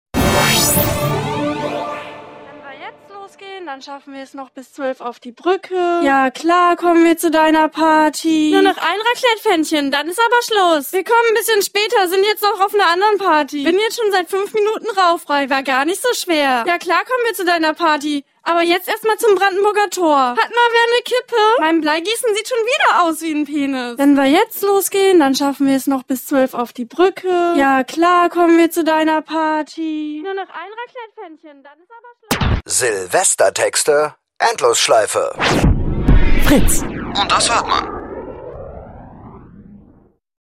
Endlosschleife "Silvestertexte 2017" | Fritz Sound Meme Jingle